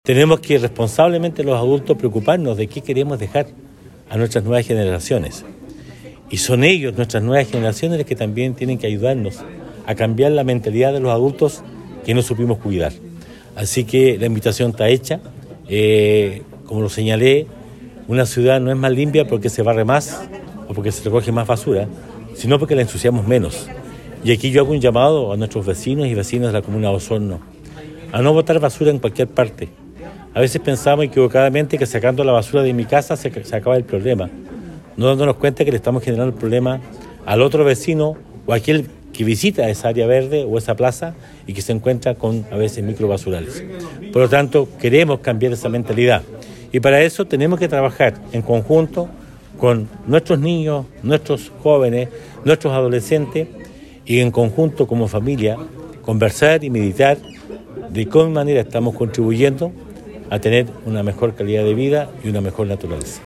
El jefe comunal señaló que es deber de los adultos poder trabajar con los niños, pues son las futuras generaciones quienes serán los encargados de proteger el ecosistema.